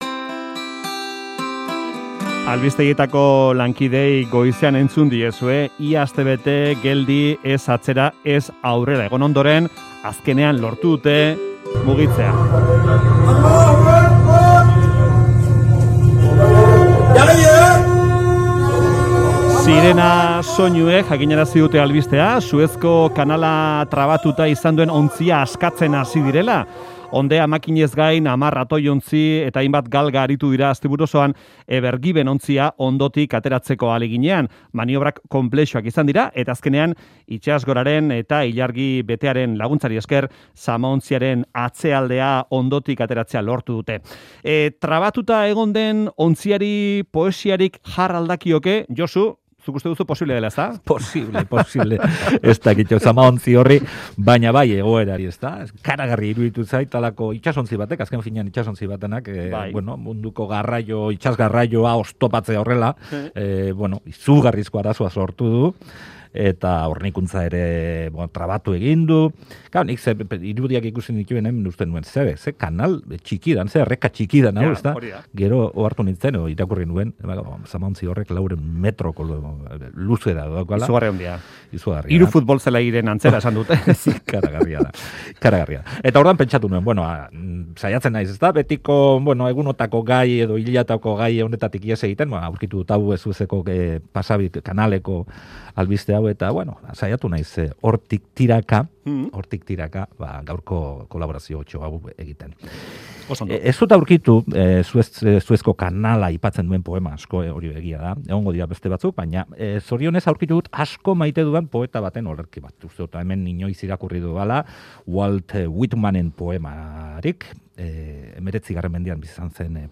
Walt Whitman, Gabriel Aresti eta Emily Dickinson olerkarien testuak errezitatu dizkigu.